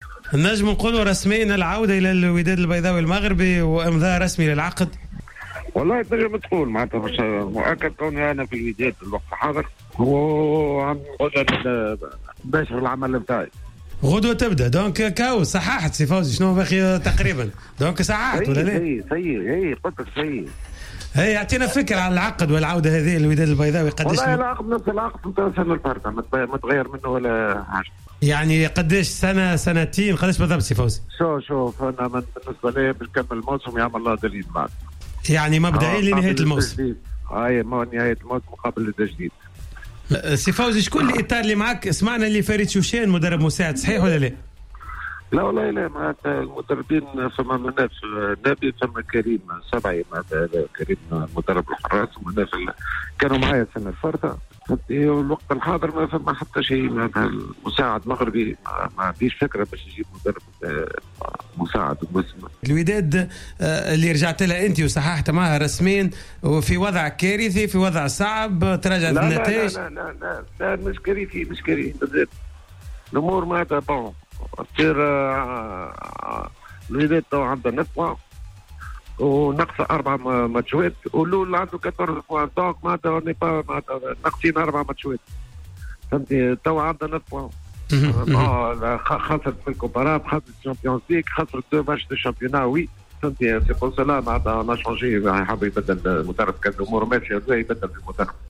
أكد المدرب فوزي البنزرتي في مداخلة في حصة Planète Sport اليوم الثلاثاء 27 نوفمبر 2018 انه قد أمضى رسميا عقدا لتدريب الوداد البيضاوي المغربي في الفترة القادمة خلفا للمدرب الفرنسي روني جيرار.